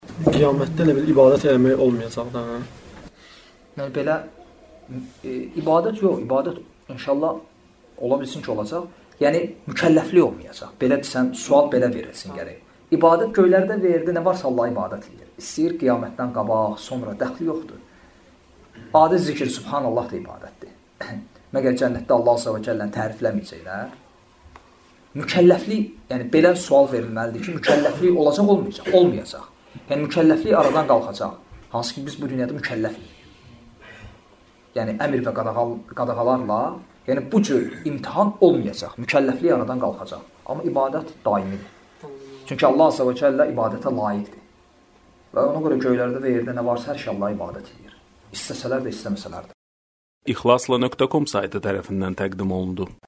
Dərslərdən alıntılar – 86 parça